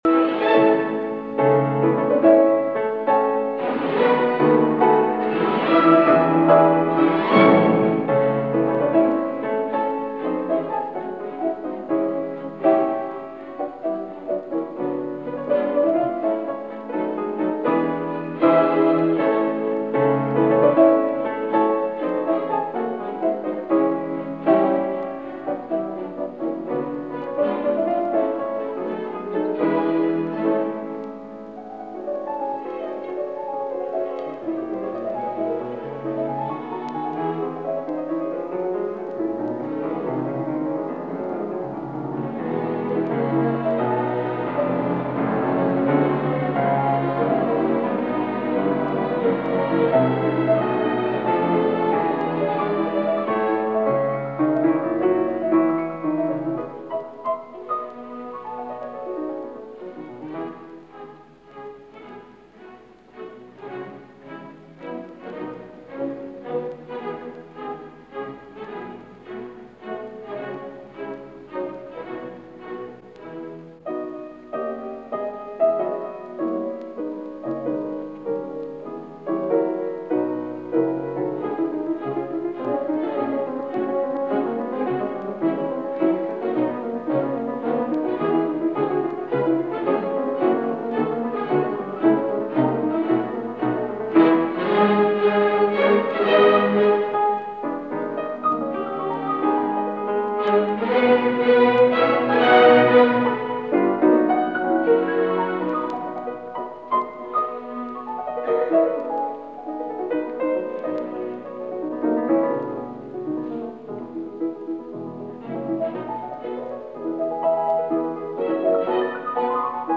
Schumann – Piano Concerto Concertgebouw Orchestra Amsterdam Donderdag, 10 oktober, 1940 ; Live recording Soloists: Emil von Sauer, piano 01 - Allegro affettuoso 02 - Intermezzo Andantino grazioso 03 - Allegro vivace